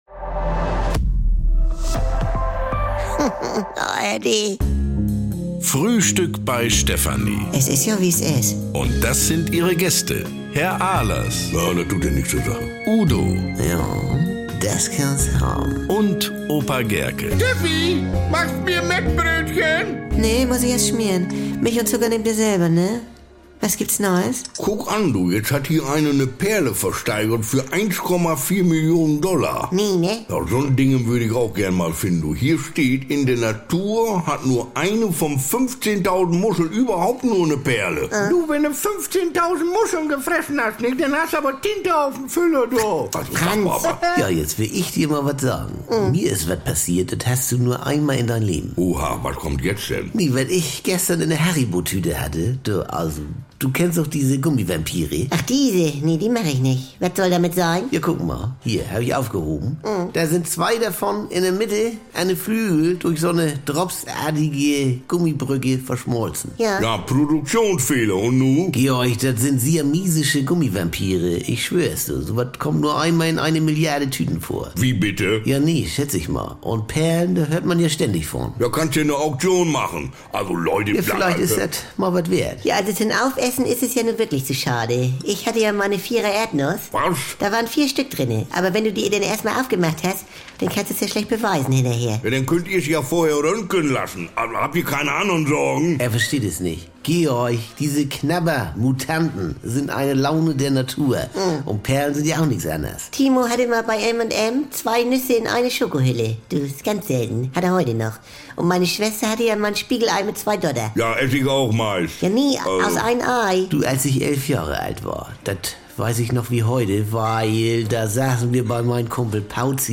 Garantiert norddeutsch mit trockenen Kommentaren, deftigem Humor und leckeren Missverständnissen.
… continue reading 651 episoder # NDR 2 # Komödie # Frühstück Bei Stefanie